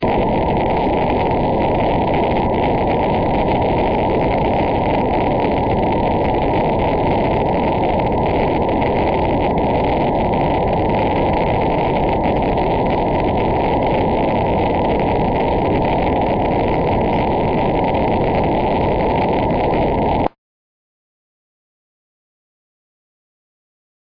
Rocket ship
Rocket ship noise science fiction sound effects.